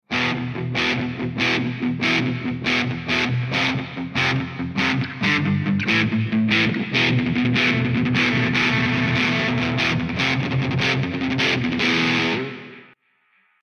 Here’s a few more experiments with the Line6 TonePort UX2 hardware / GearBox software, this time at the Paint Branch Ramblers practice for November 20, 2008.
This is from some fooling around before practice
I think this was recorded by running a line directly out of his acoustic guitar through the “Ain’t Talkin’ ‘Bout Love” guitar setting on the GearBox.